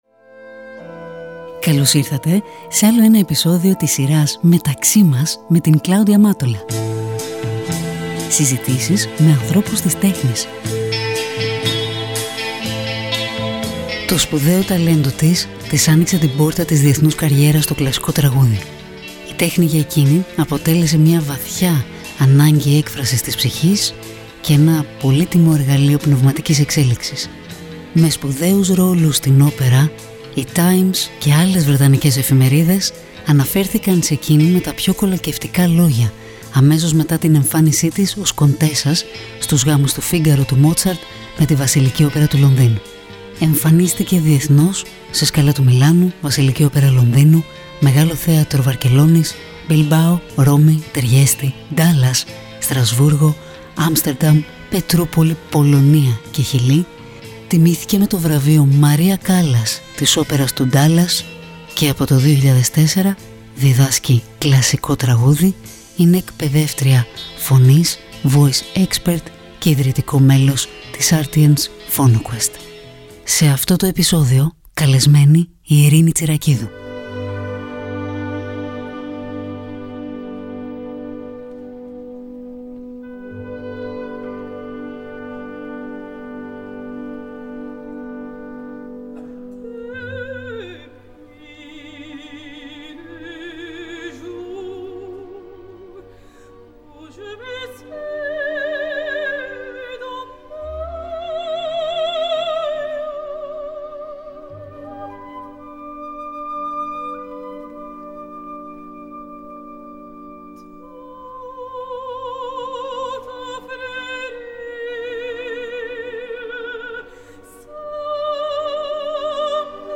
“Μεταξύ μας” ένα podcast με την υπογραφή του Kosmos Μια οργανική συζήτηση με ανθρώπους της τέχνης.